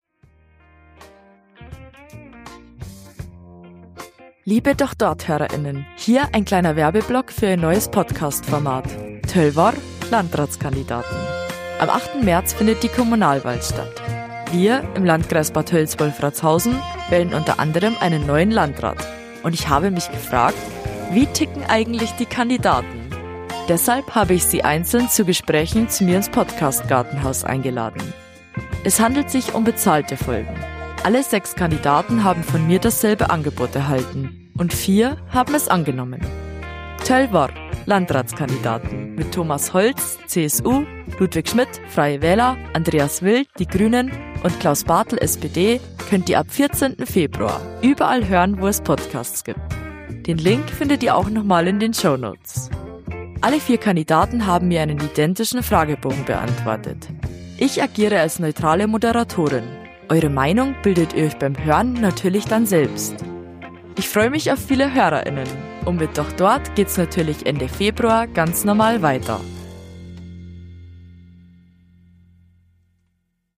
:) Musik: Die Reiwas